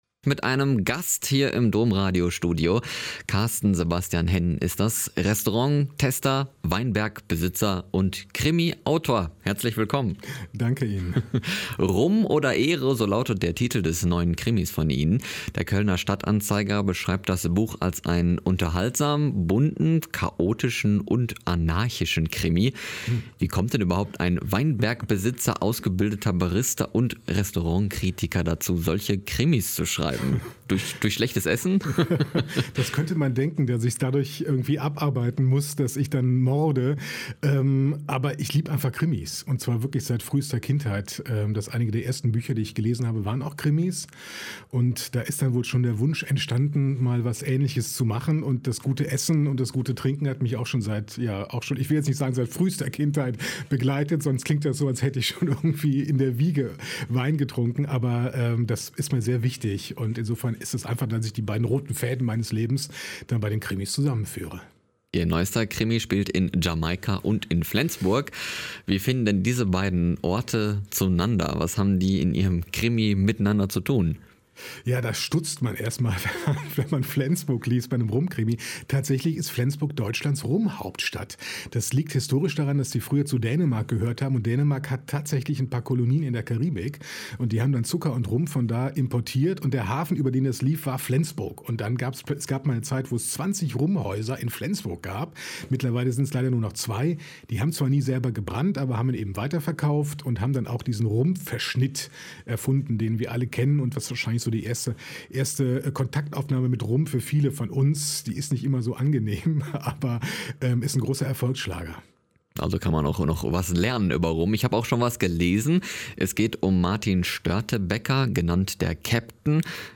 Ein Interview mit Carsten Sebastian Henn (Autor und Weinliebhaber)